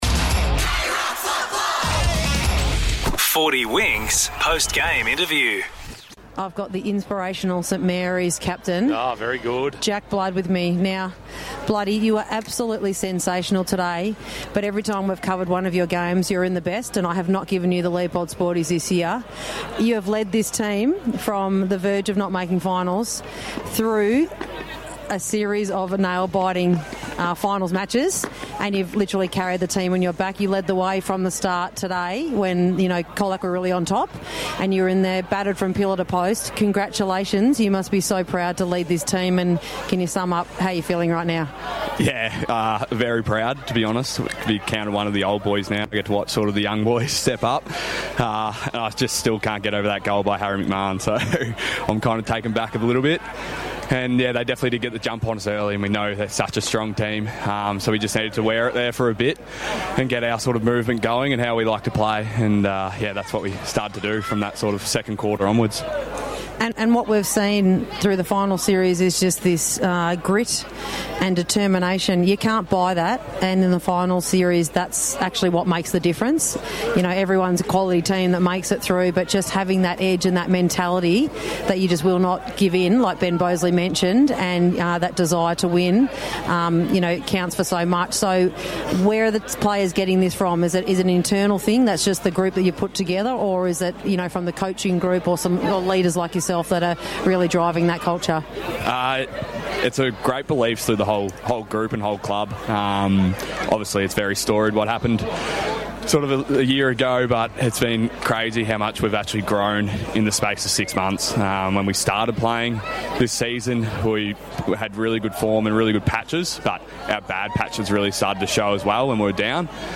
2025 - GFNL - Preliminary Final - Colac vs. St Mary's - Post-match interview